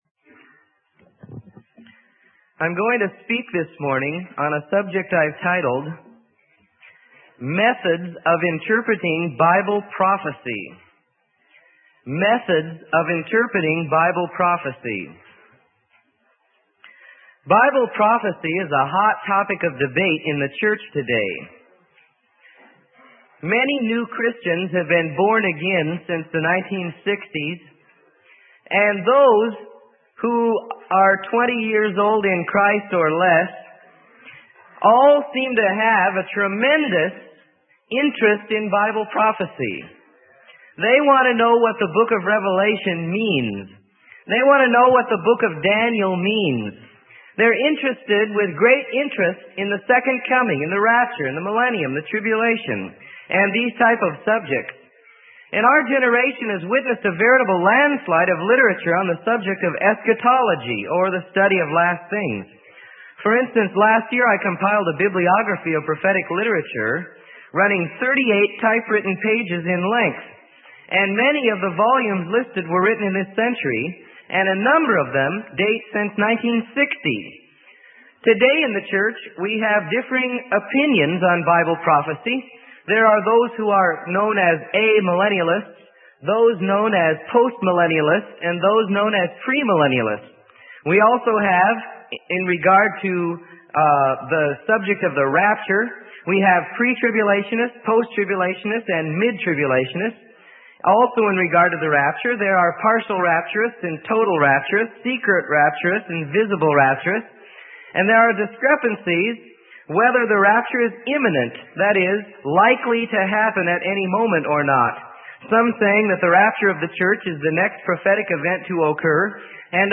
Sermon: Methods of Interpreting Bible Prophecy - Freely Given Online Library